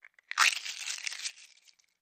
Loose Egg Shells Crunching Together